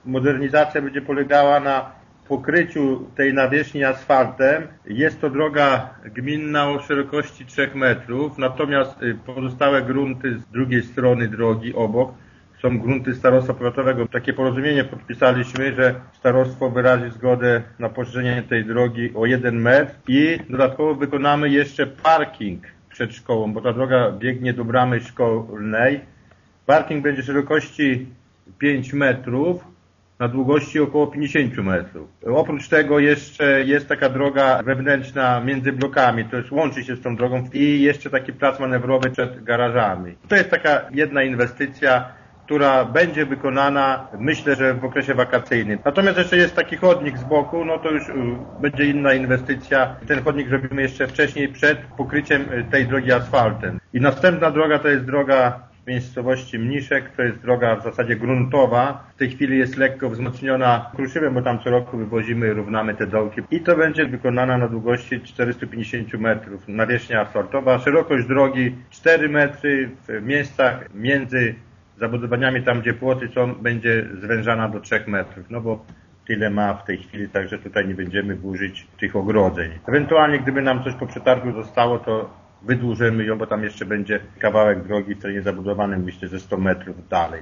„Betonowa trasa prowadząca do Zespołu Szkół w Wólce Gościeradowskiej ma być zmodernizowana jeszcze przed rozpoczęciem nowego roku szkolnego” – informuje wójt Jan Filipczak: